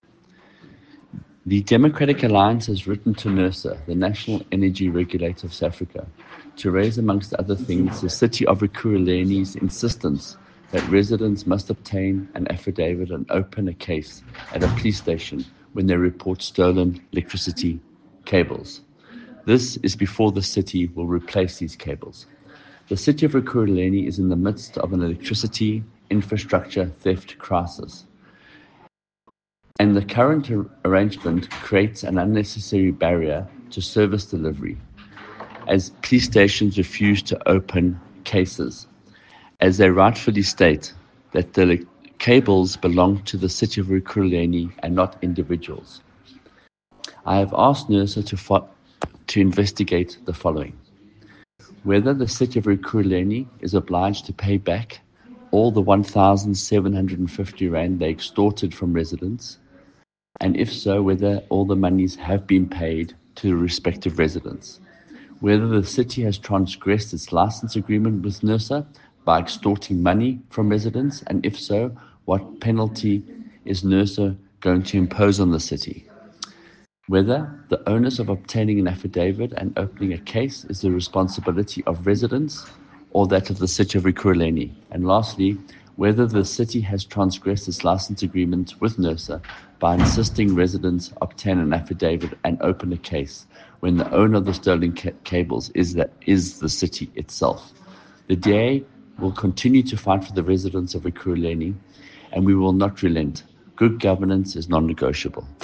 Note to Editors: Please find an English soundbite by Michael Waters MPL